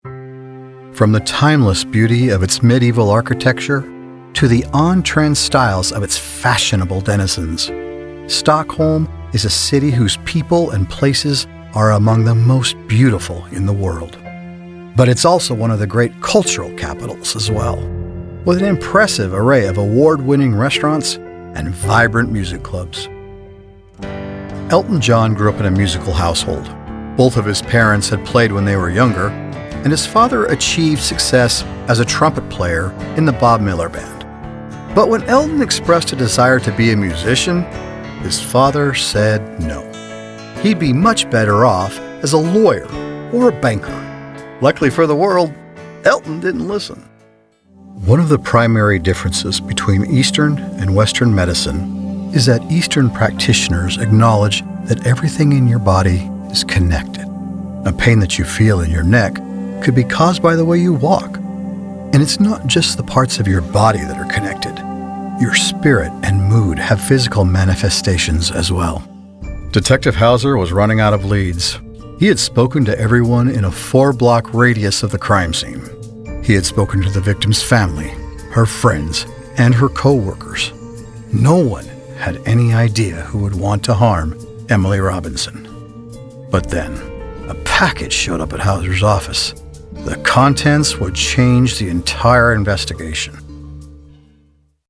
Here are demos of my Commercial and Narrative work.